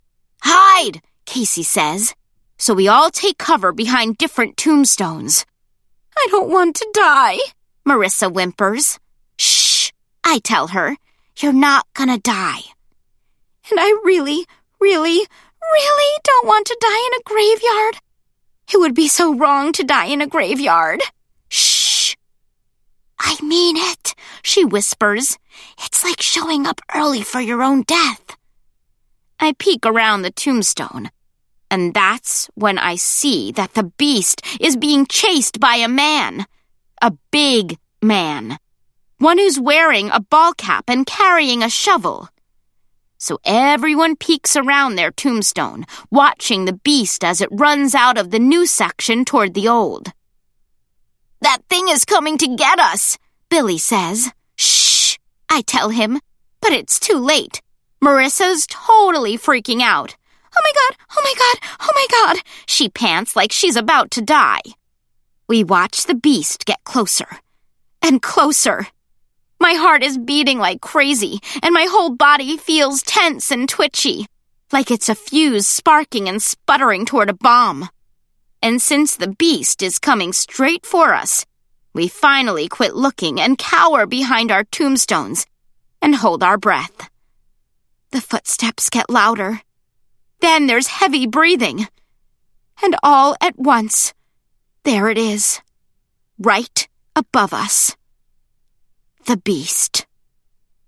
Home  >  Middle Grade Audiobooks